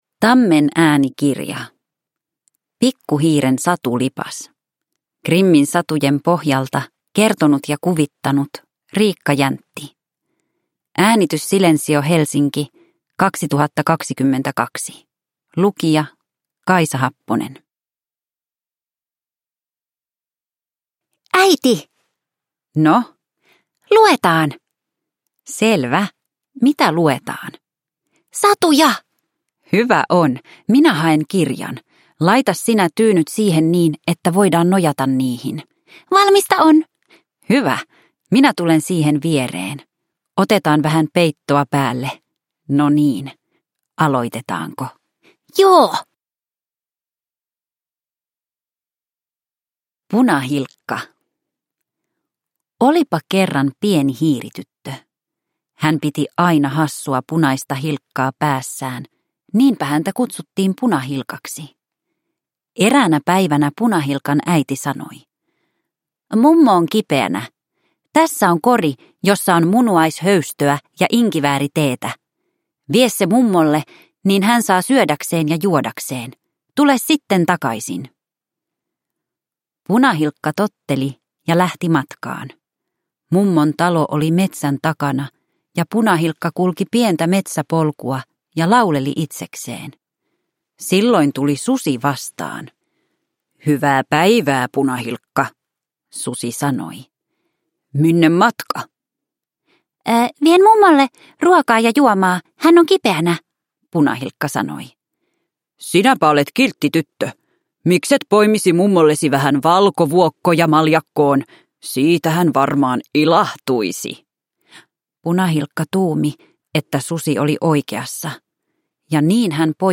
Pikku hiiren satulipas – Ljudbok